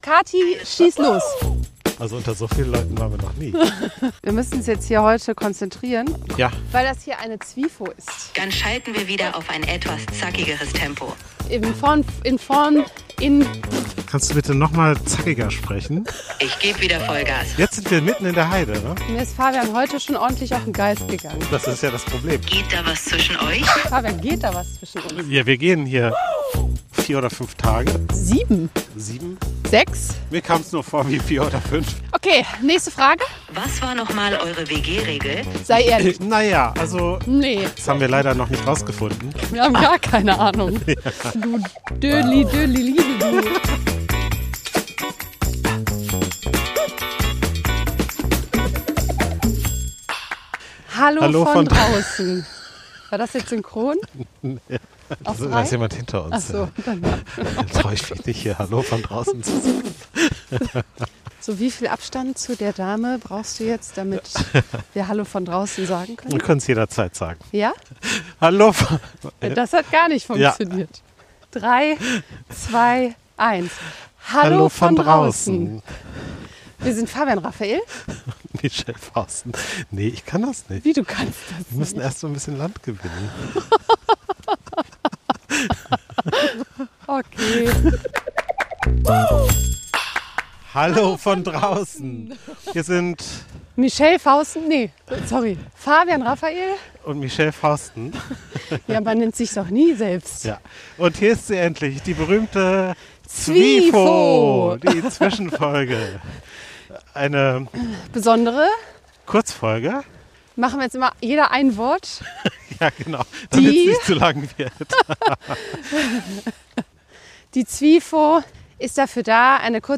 Für die Aufzeichnung der ZwiFo sind wir in die schöne Wahner Heide gefahren, vor den Toren von Köln und Bonn.